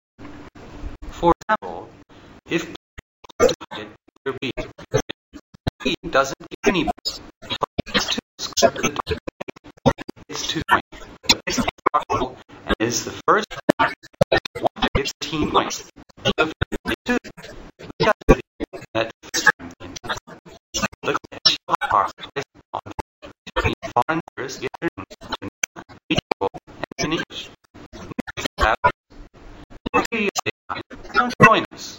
ＲＥＡＤＩＮＧ
(slow)